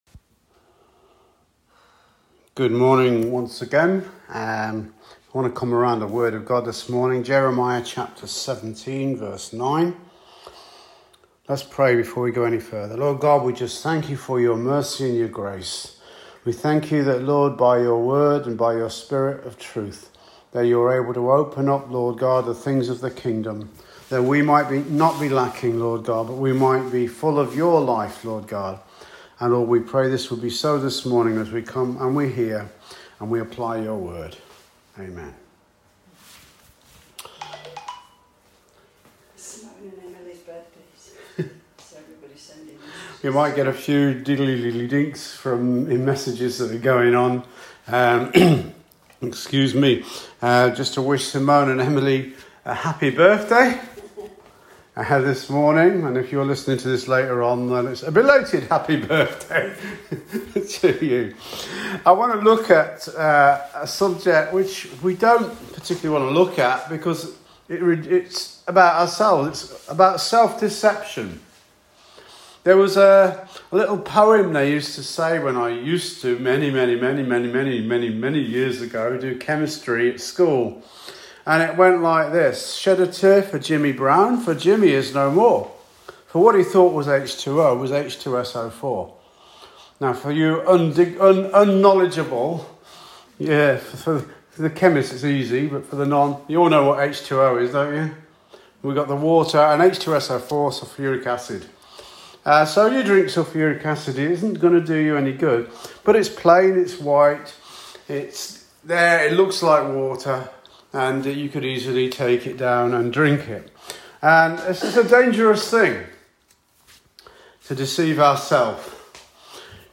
Calvary Chapel Warrington Sermons